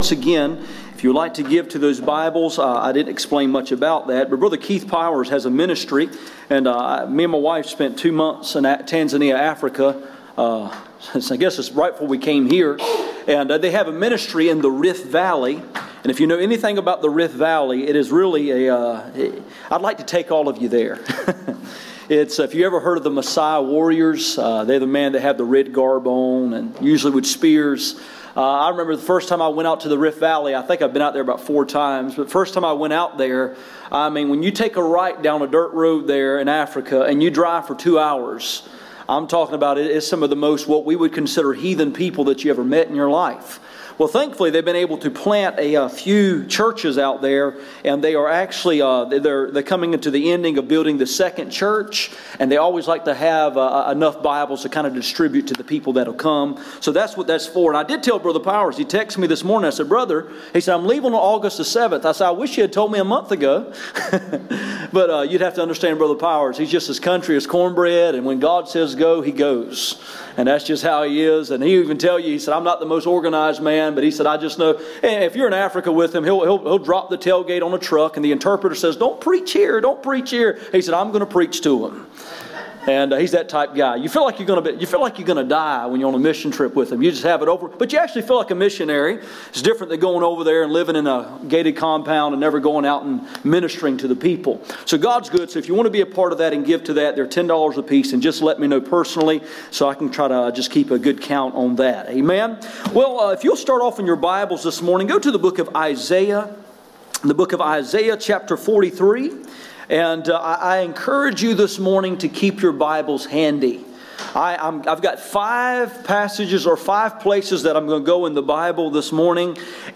Isaiah 43:25-42:25 Service Type: Sunday Morning %todo_render% « The Kingdom and Kids What did God do with my sins